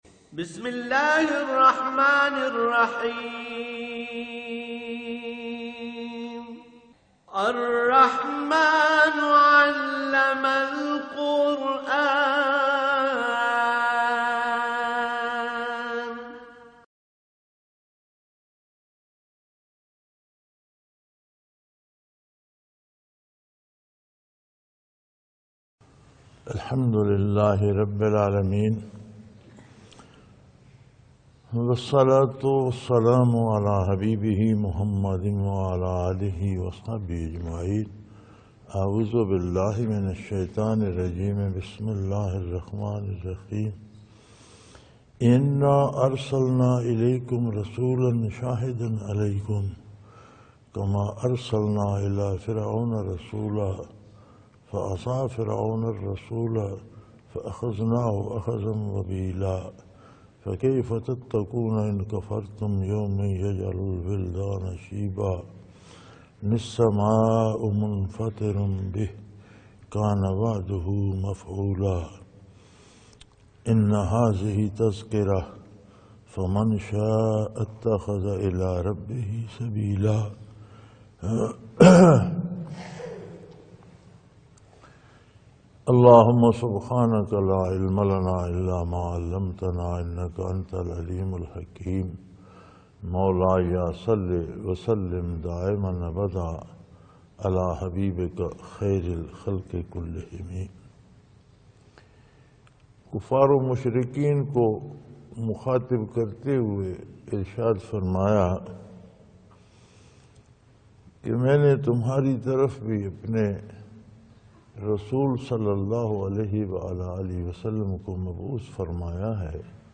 Lectures in Munara, Chakwal, Pakistan on December 12,2016